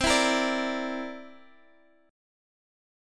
C9 chord